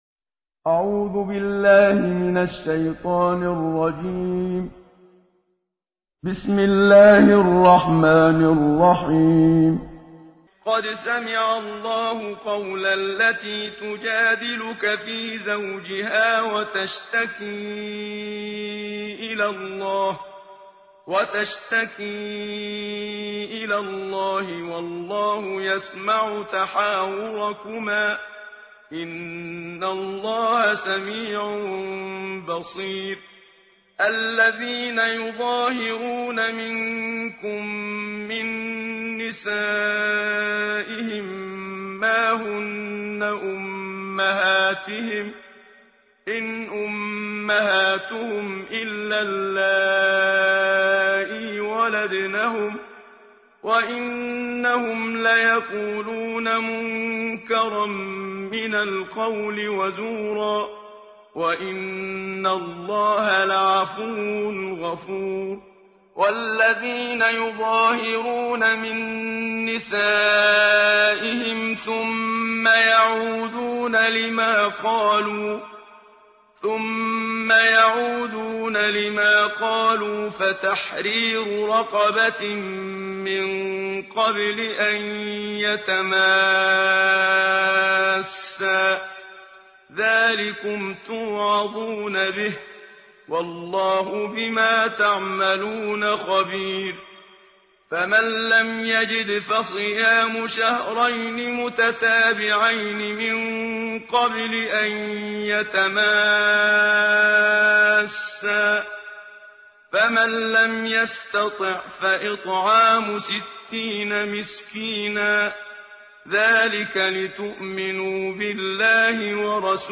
ترتیل جزء بیست و هشتم قرآن با صدای استاد منشاوی
تهران- الکوثر: در بیست و هشتمین روز ماه مبارک رمضان، تلاوت جزء بیست و هشتم قرآن کریم را با صدای قاری مشهور مصری استاد محمد صدیق منشاوی، با هم می شنویم.